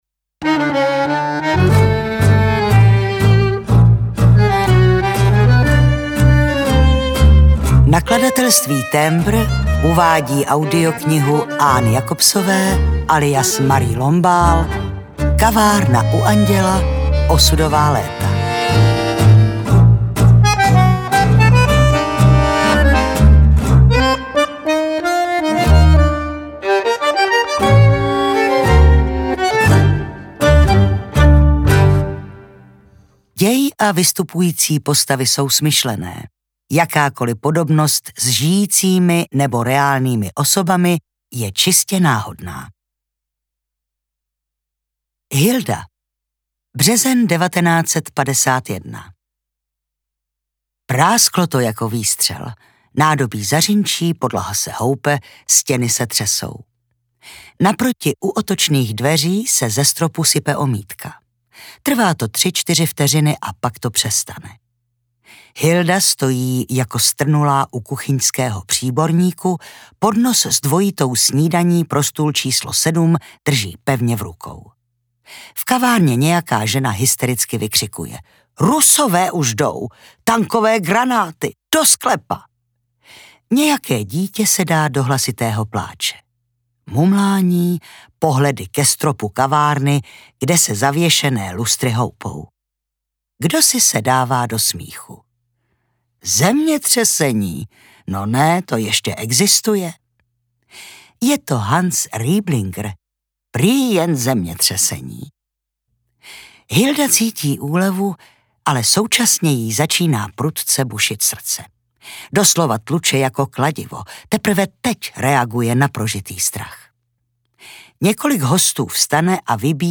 Kavárna u Anděla 2: Osudová léta audiokniha
Ukázka z knihy